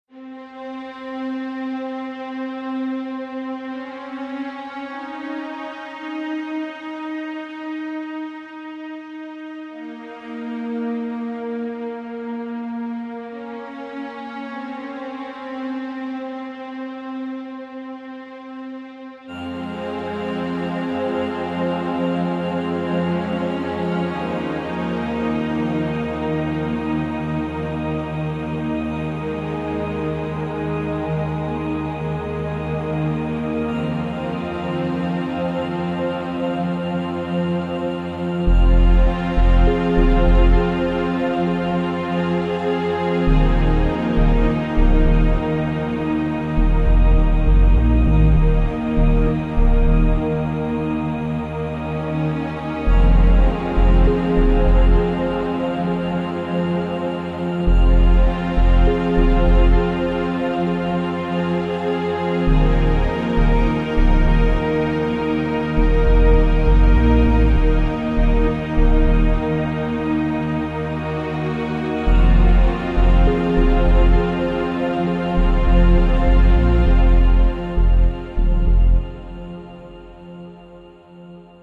zone_field_mine.wav